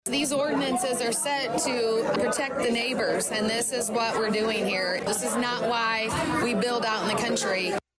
A capacity crowd of about 50 filled the City Hall Chambers; with 20 residents, some of them speaking more than once, all speaking against the Summit Ridge Energy proposal, which would be on land owned by Martin Farms out of Indiana.